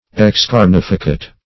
Excarnificate \Ex*car"ni*fi*cate\, v. t.
excarnificate.mp3